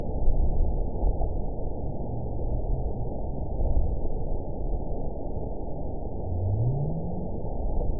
event 920577 date 03/30/24 time 23:37:28 GMT (1 year, 1 month ago) score 9.64 location TSS-AB01 detected by nrw target species NRW annotations +NRW Spectrogram: Frequency (kHz) vs. Time (s) audio not available .wav